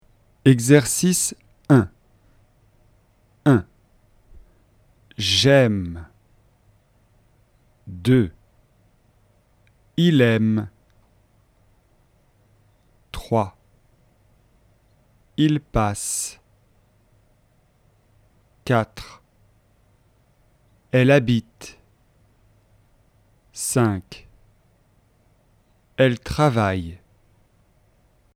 Règle : On ne prononce pas le E placé en fin de mot.
⚠ Quand le mot termine par S, on ne prononce pas -E S.